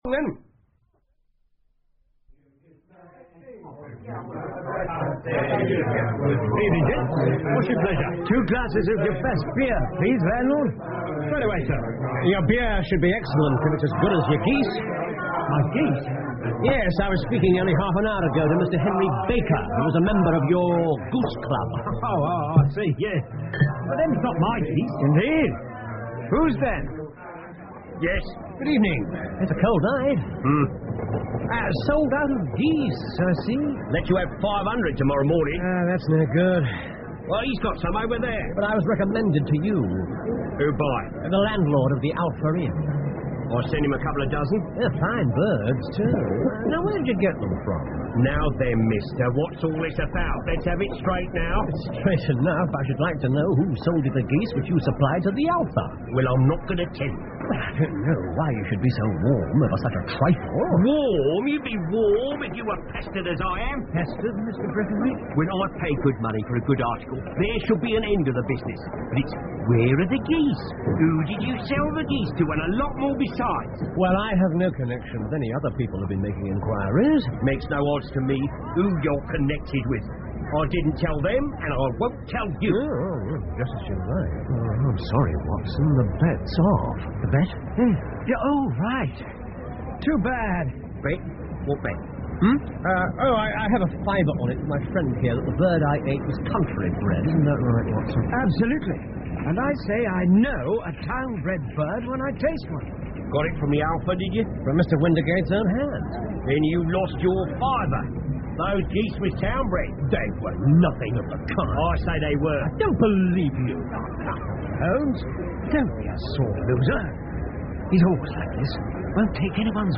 福尔摩斯广播剧 The Blue Carbuncle 6 听力文件下载—在线英语听力室